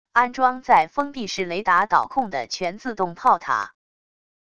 安装在封闭式雷达导控的全自动炮塔wav音频